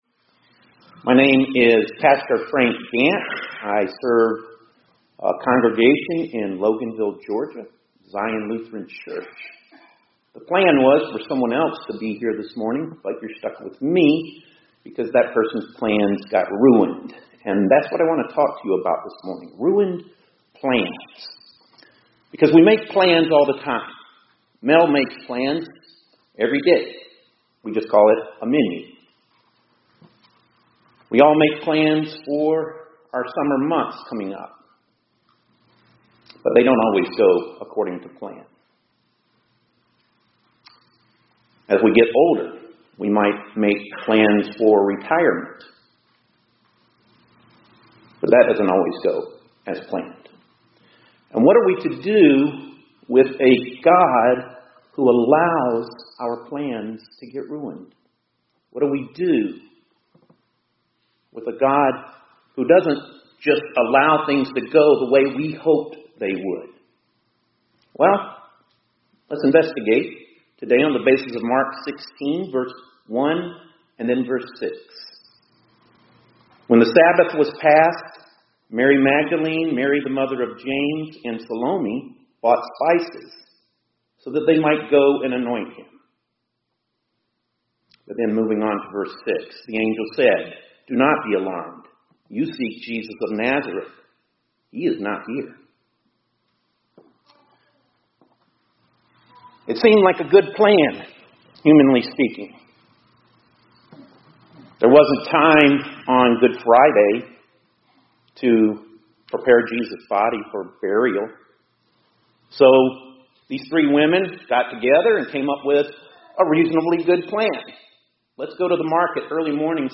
2025-04-28 ILC Chapel — God Ruins the Plans